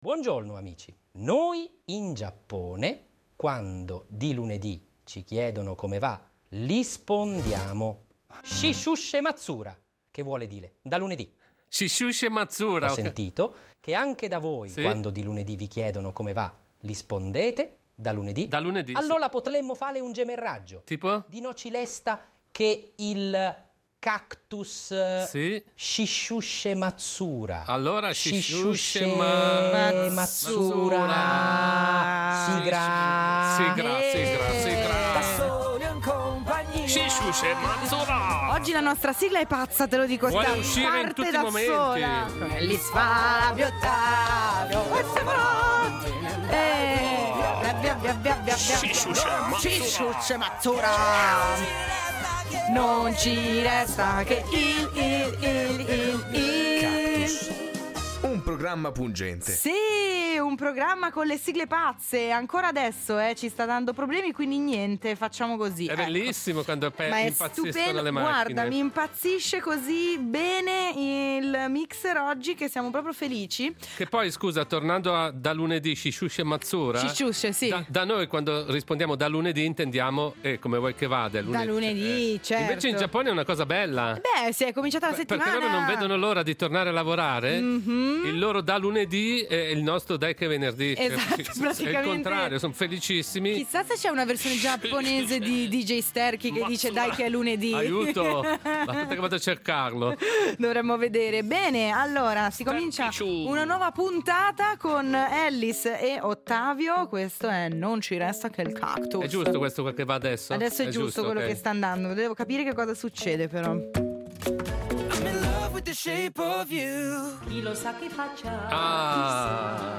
vengono attaccati da onde radio che disturbano il funzionamento del mixer in diretta.